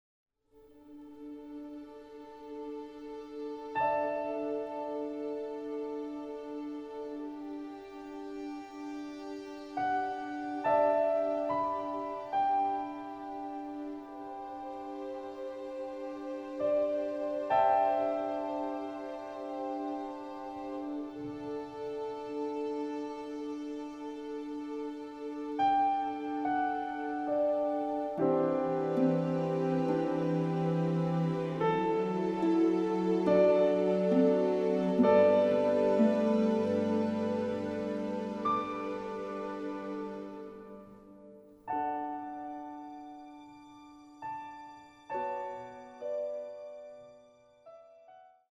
emotionally charged pieces
CHARMING SOUNDTRACK